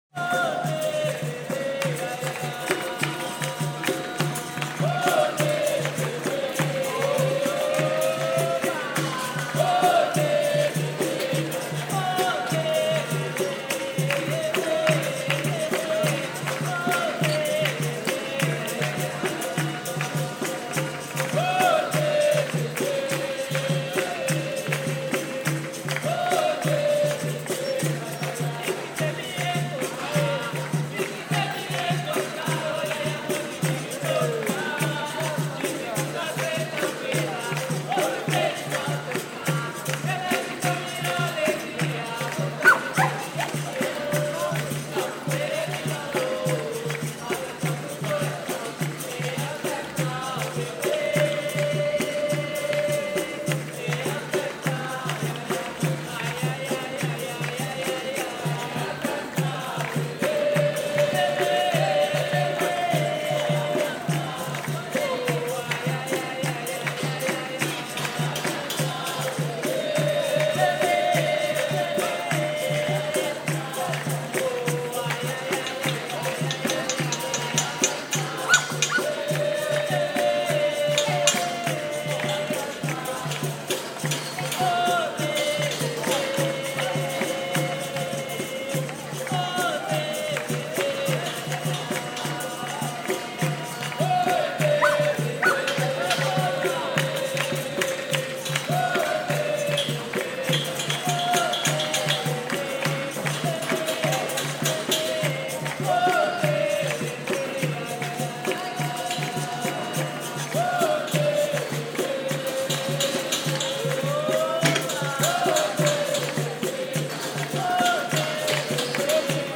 Street dancing and impromptu performance.